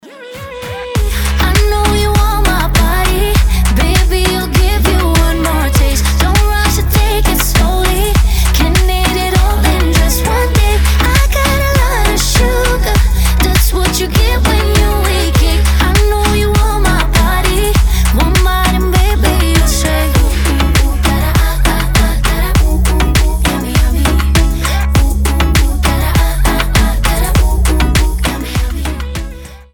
заводные
dancehall
дуэт